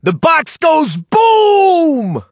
brock_ulti_vo_01.ogg